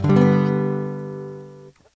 描述：用廉价的木吉他弹了几下。
标签： 吉他 乱弹
声道立体声